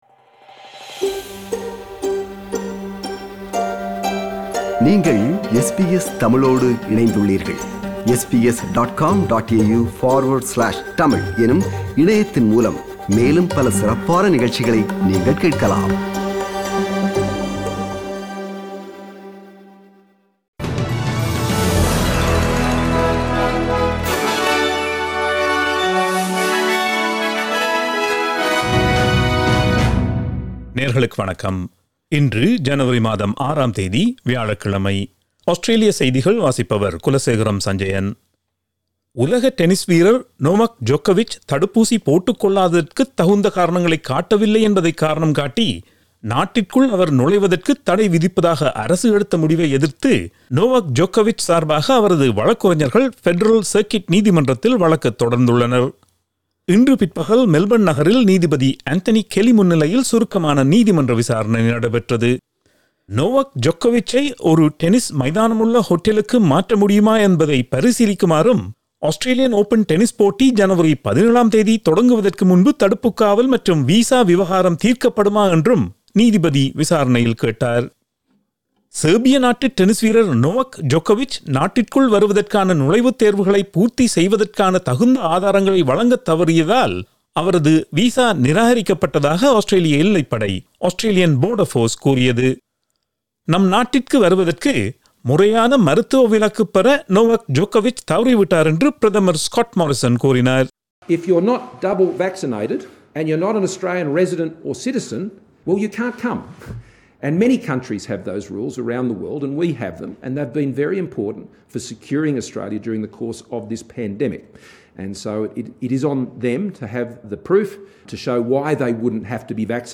Australian news bulletin for Thursday 06 January 2022.